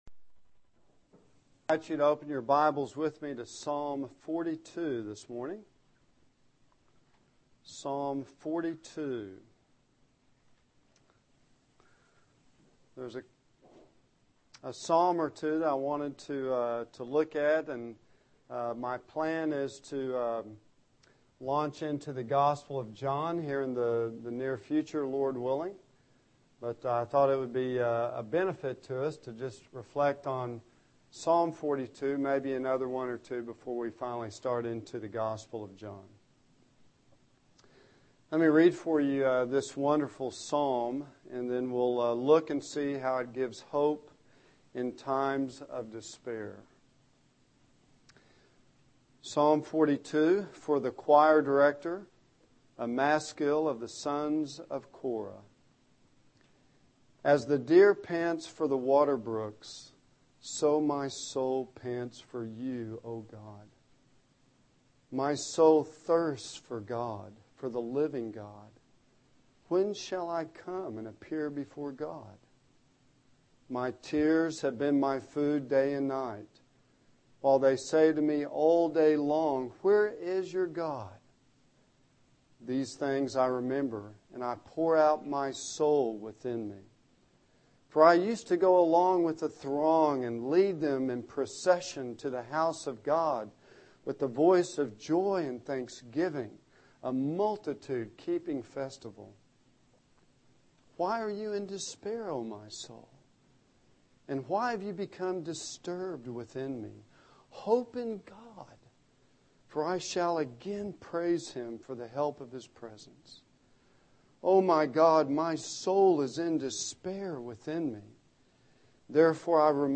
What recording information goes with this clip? Psalm 22 Service Type: Sunday AM